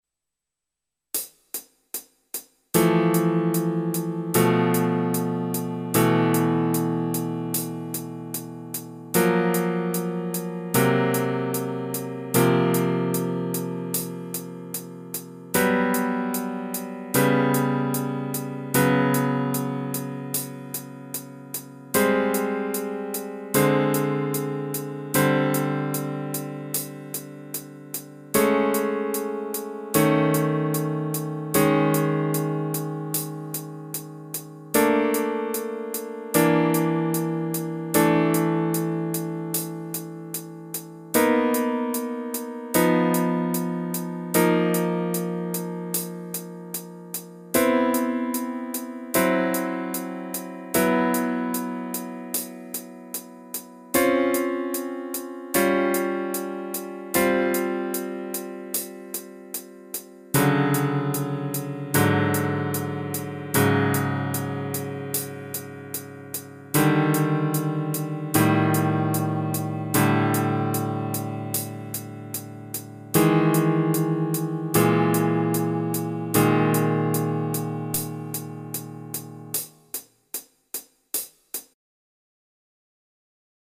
strumenti-musica-fisarmonica-accordion-pillole-di-jazz-lezione-5-es-2-ii-v-i-maggiore-seconda-posizione.mp3